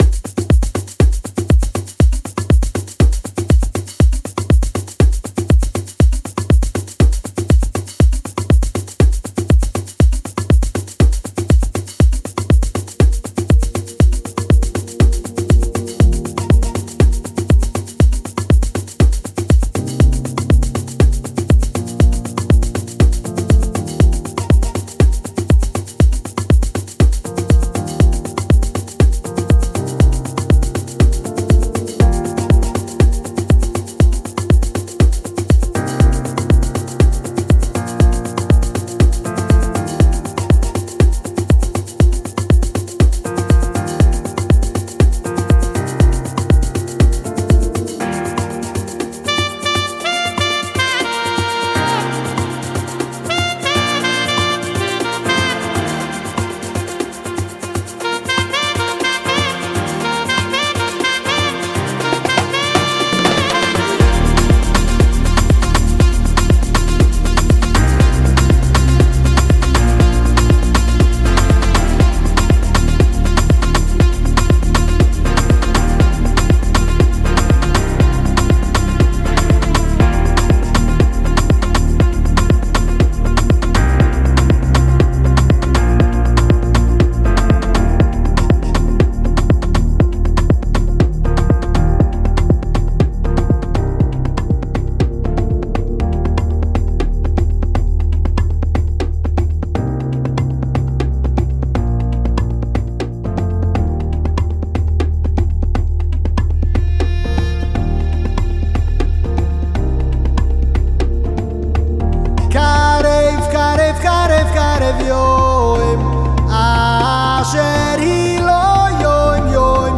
פתיח של למעלה מדקה
מקהלות
דחס צרור של פראזות קצרות בזה אחר זה